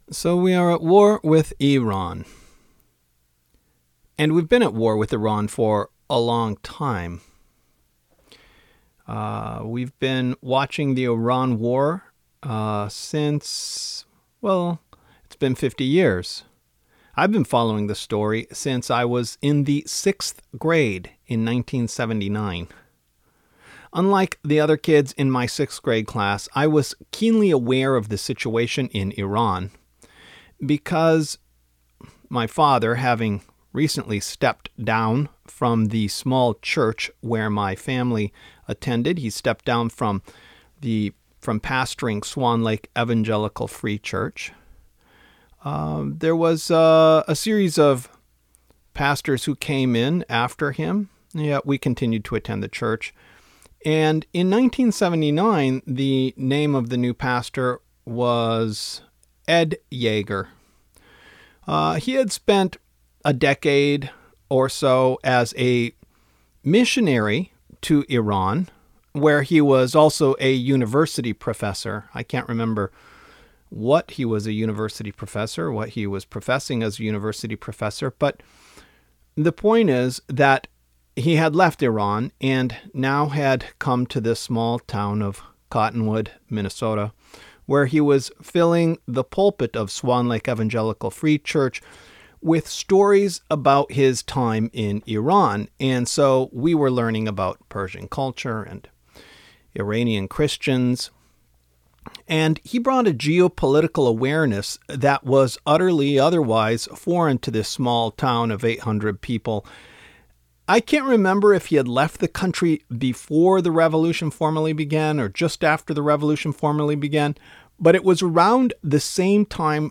A teaching from the seven day of Passover about the war with Iran and the Iranian revolution.